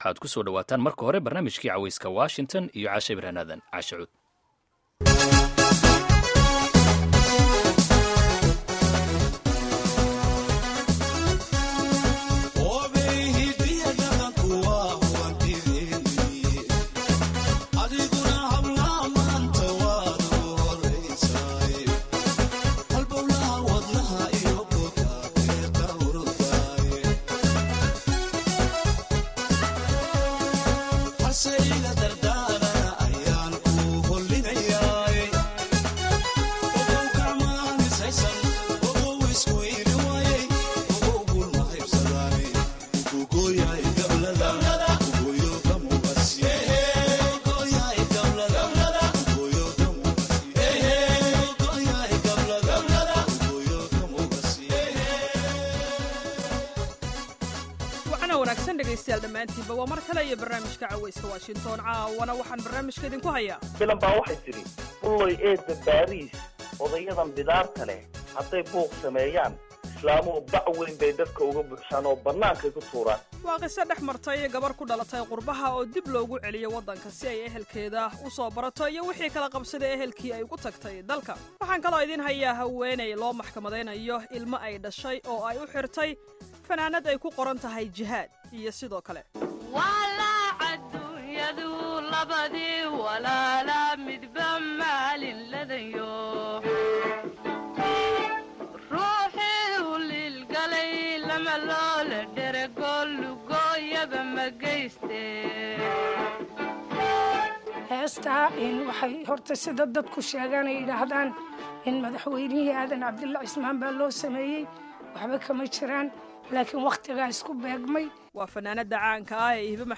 Barnaamijka Caweyska ee todobaadkan waxaad ku maqli doontaan qodobo si gaar ah xiisa u leh. Waxaana ka mid ah qeybtii ugu horeysay ee wareysi aan la yeelanay fanaanadda Soomaaliyeed ee Hiba Maxamed Hiba Nuura.